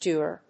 /ˈduːɑː(米国英語)/